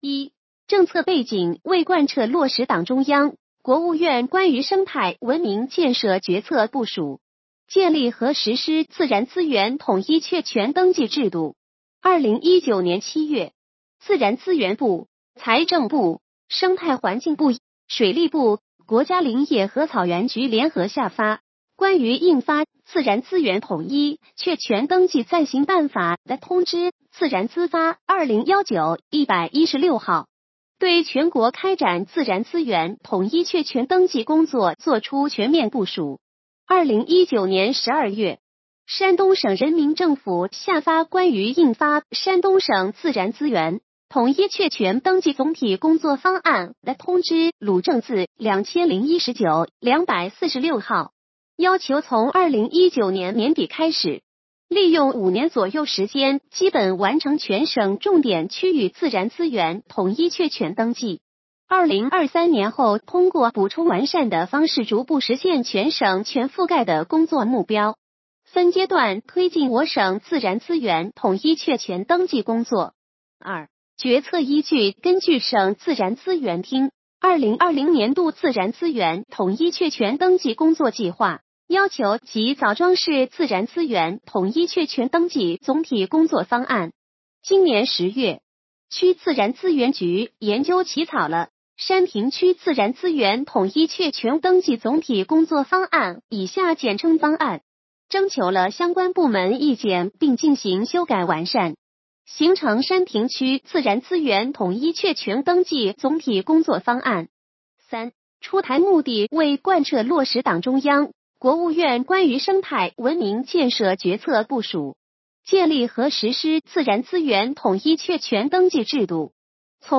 语音解读：山亭区人民政府关于印发山亭区自然资源统一确权登记总体工作方案的通知